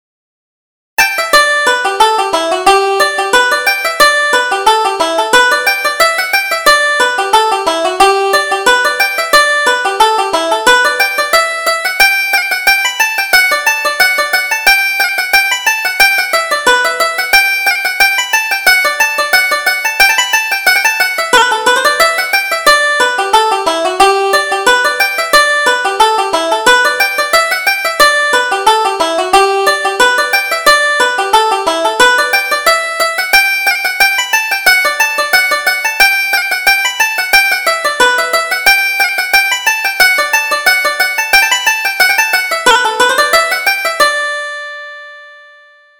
Reel: The First of March